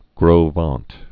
(grō vänt)